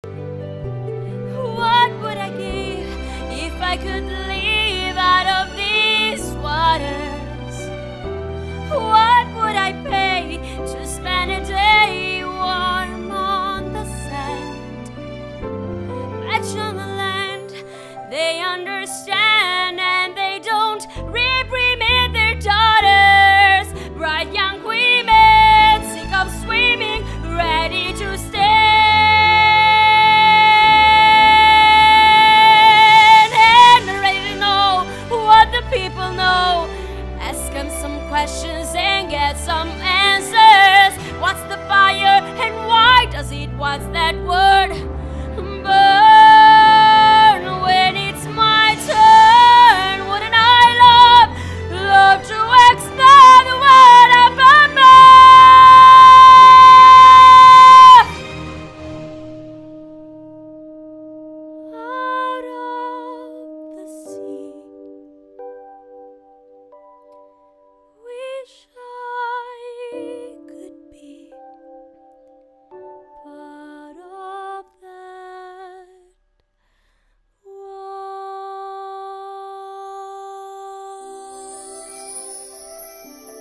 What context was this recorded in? I want to specify that I was not very close to the microphone! I mean I wasn't far but I wasn't even close. I tried to have a right distance... I didn't put any reverb so that you can listen well...